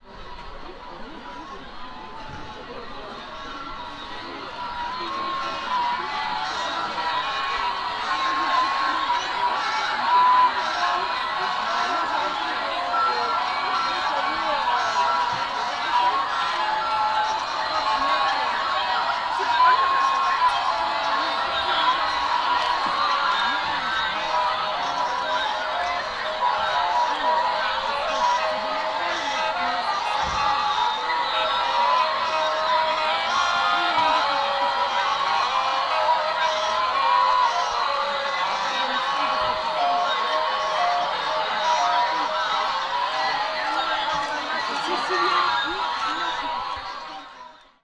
Strasbourg, Christkindelsmärik - Nagra Lino